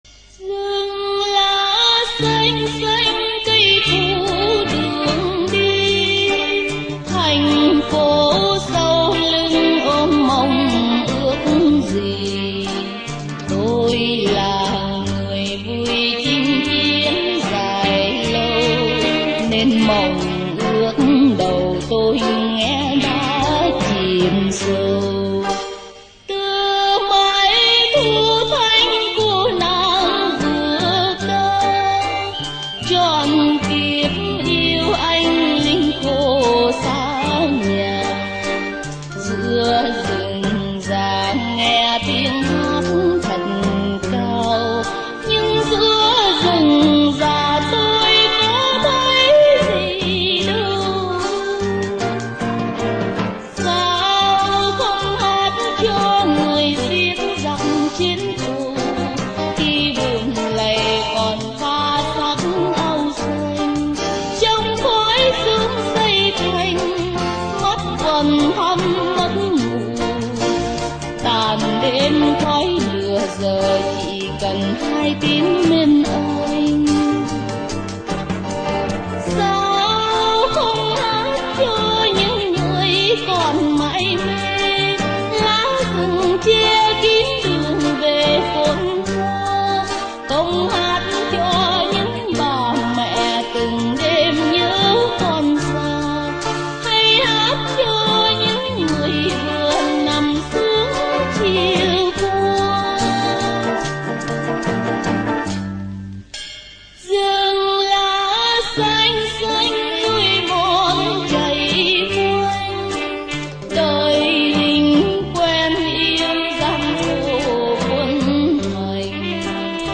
Nhạc của ông buồn nhưng không ủy mị.